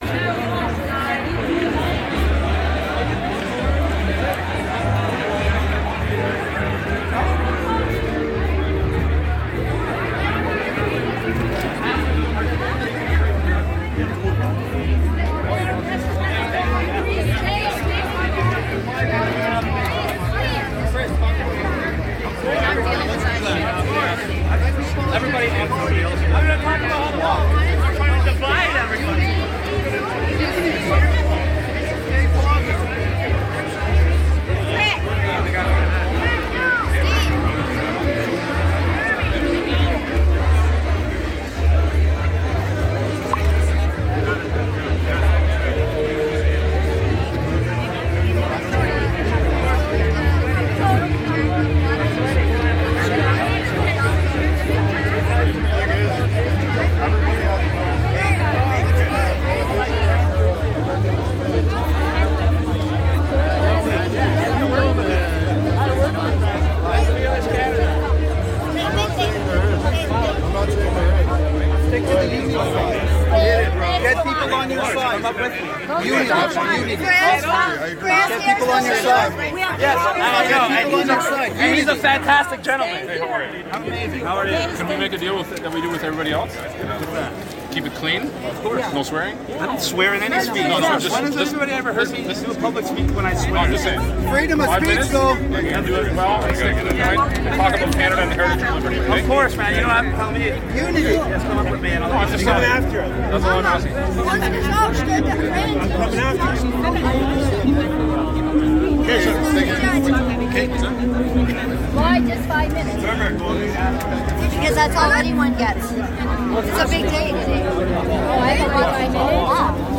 speech interrupted